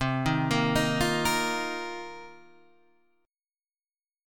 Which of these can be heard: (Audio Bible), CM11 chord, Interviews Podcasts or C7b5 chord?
CM11 chord